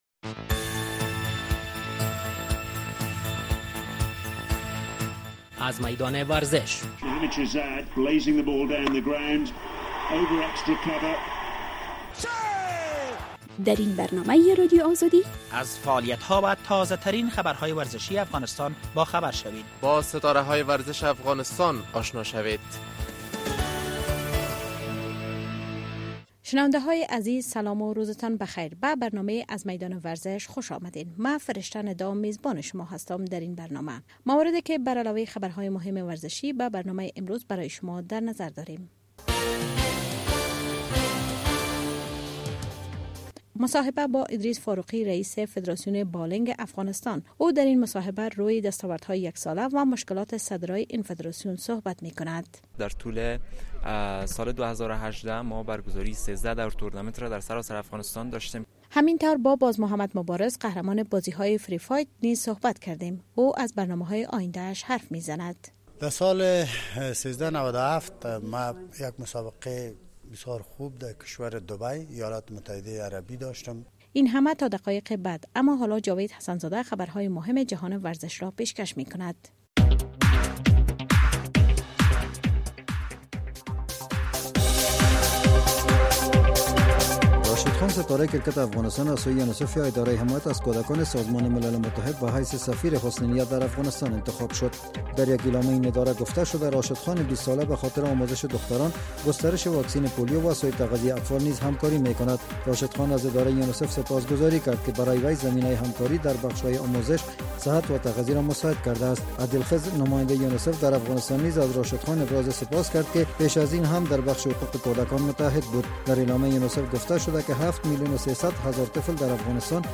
موارد که برعلاوه خبرهای مهم ورزشی به برنامه امروز برای شما در نظر داریم. مصاحبه